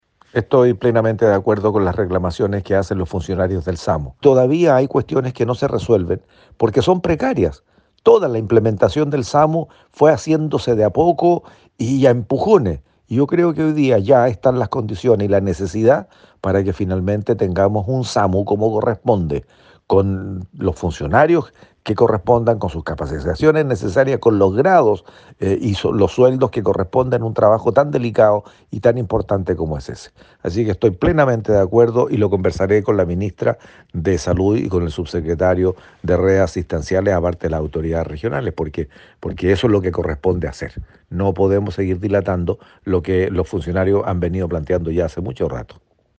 Cuna-senador-Flores-por-movilizacion-de-funcionarios-SAMU.mp3